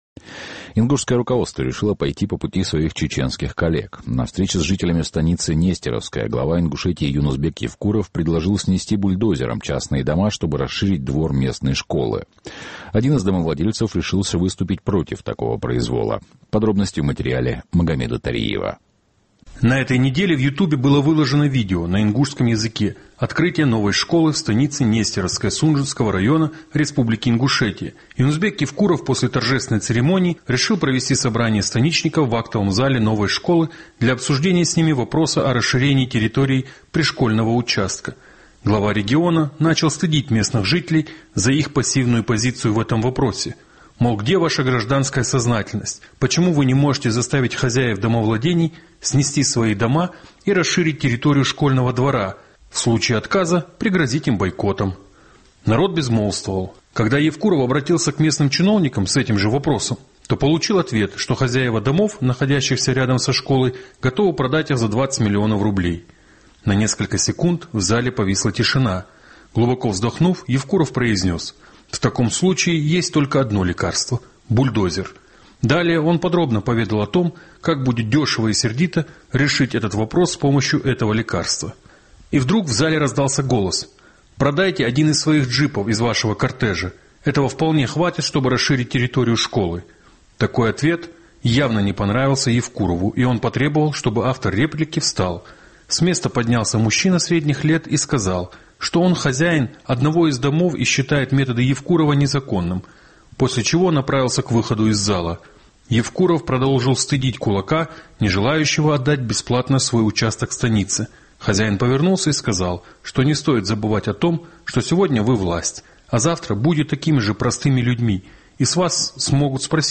На встрече с жителями станицы Нестеровская Евкуров предложил снести бульдозером дома, чтобы расширить двор местной школы.